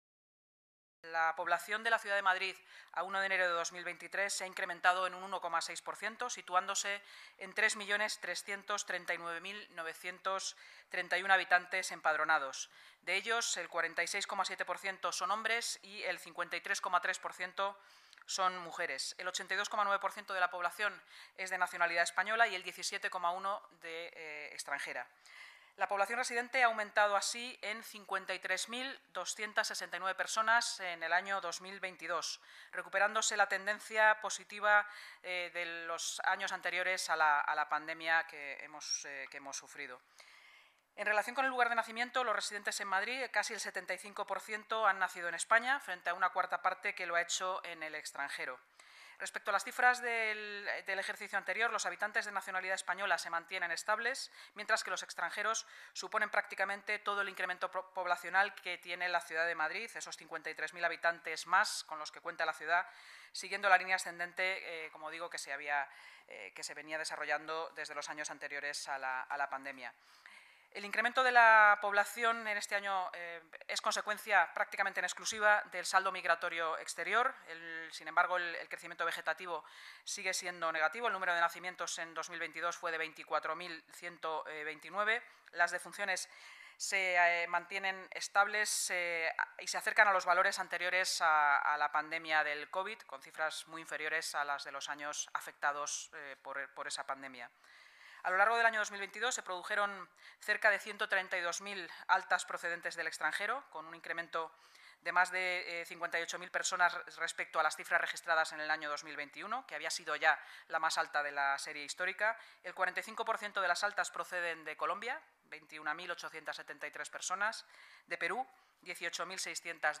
Nueva ventana:Así lo ha contado esta mañana, la portavoz municipal, Inmaculada Saz, tras la celebración de la Junta de Gobierno: